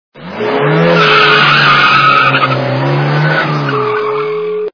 » Звуки » Авто, мото » Быстро едущая машина - Порше
При прослушивании Быстро едущая машина - Порше качество понижено и присутствуют гудки.
Звук Быстро едущая машина - Порше